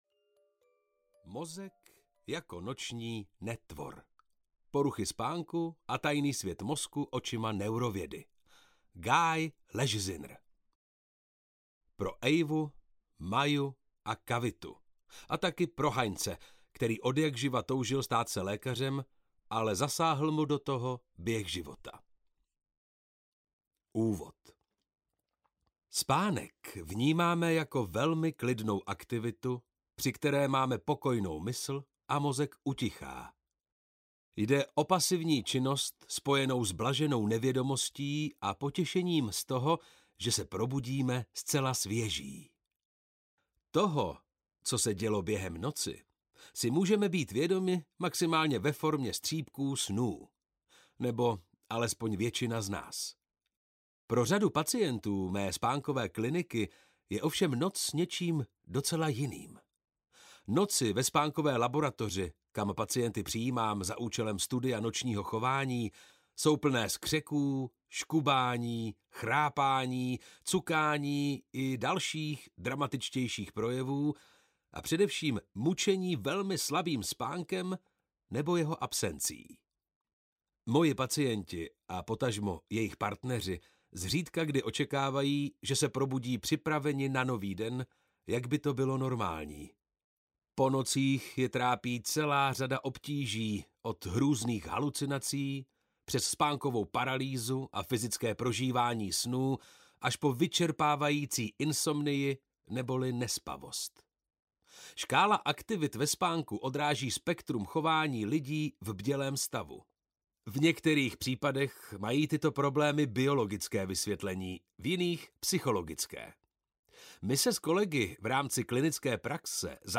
Mozek jako noční netvor audiokniha
Ukázka z knihy